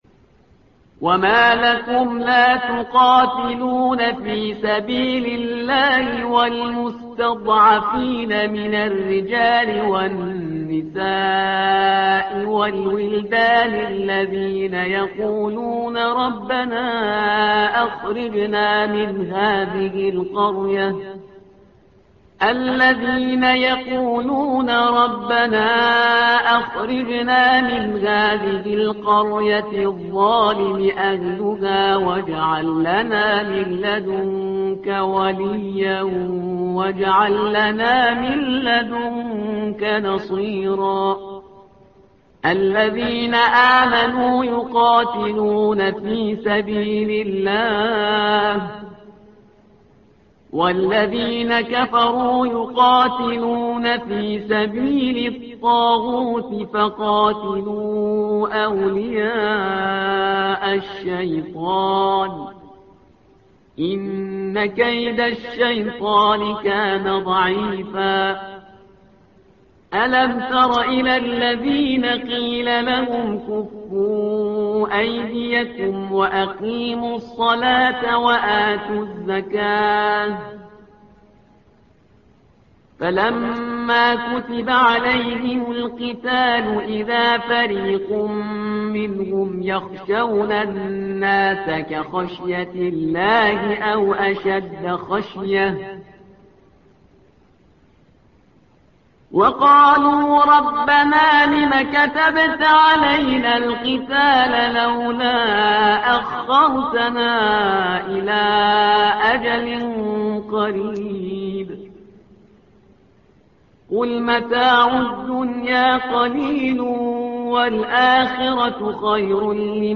الصفحة رقم 90 / القارئ